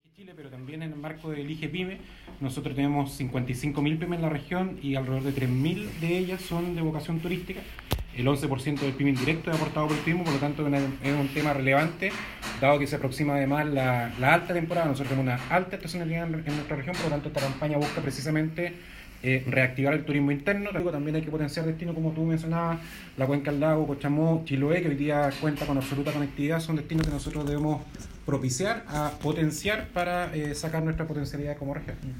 cuña-seremi-Economía-Francisco-Muñoz.mp3